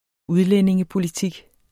Udtale [ ˈuðlεneŋəpoliˌtig ]